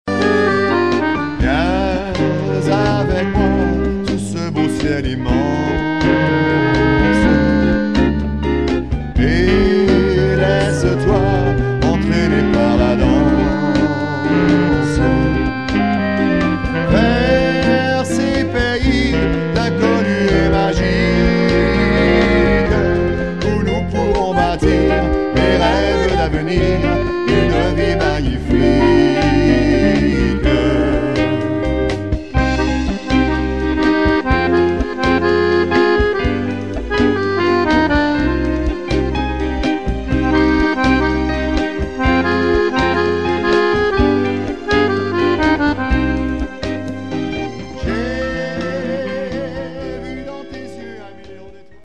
Boléro chanté